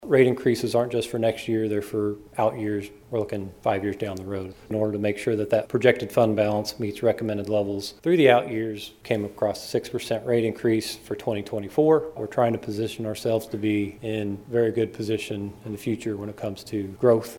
Additionally on Tuesday, commissioners discussed the 2023 water, wastewater and stormwater utilities and updates to rates and charges for 2024.